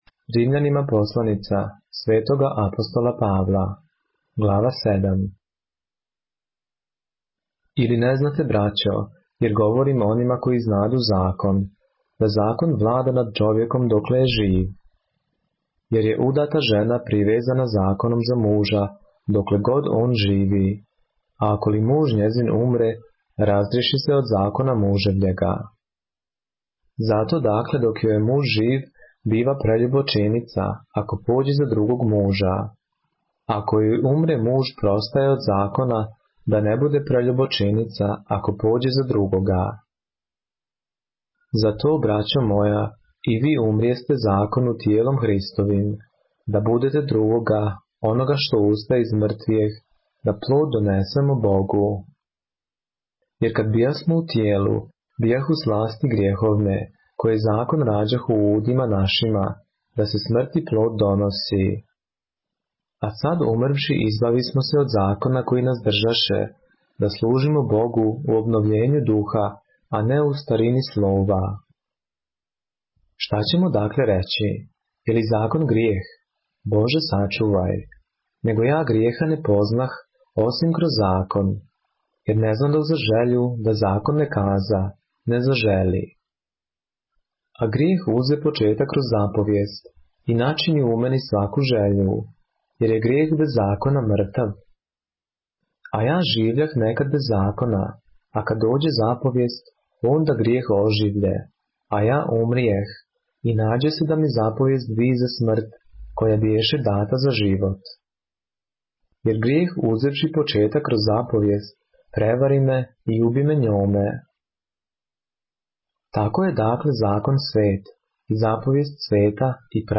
поглавље српске Библије - са аудио нарације - Romans, chapter 7 of the Holy Bible in the Serbian language